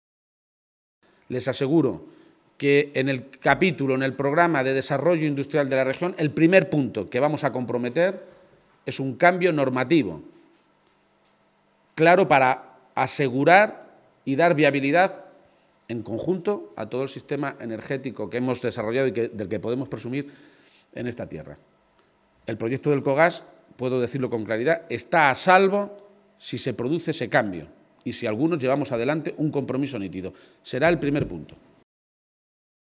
El candidato a Presidente de Castilla-La Mancha hacía estas declaraciones en una comparecencia ante los medios de comunicación en la ciudad minera, después de mantener una reunión con el Comité de Empresa de Elcogás, donde ha recibido información de primera mano de cómo está la negociación que están llevando a cabo tanto con el ministerio de industria, como con la propia empresa, que ha anunciado el cierre en diciembre si el Estado no cambia el marco que regula las ayudas públicas a este tipo de industrias energéticas.